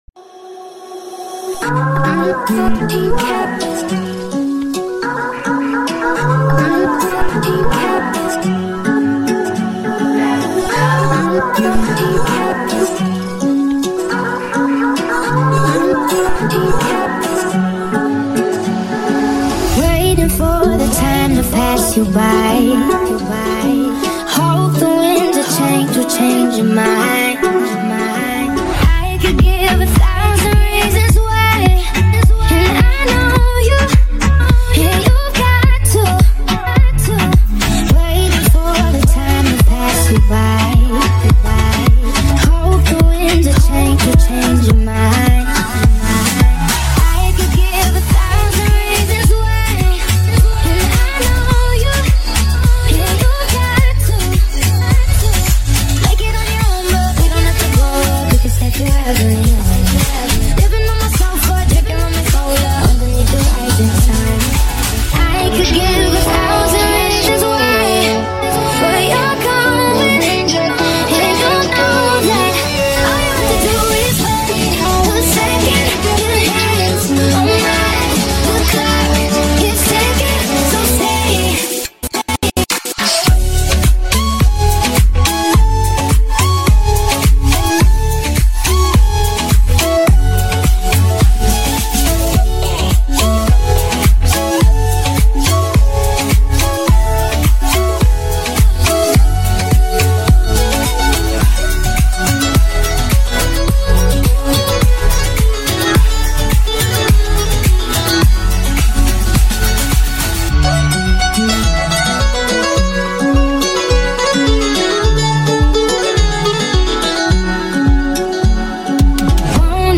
High quality Sri Lankan remix MP3 (5).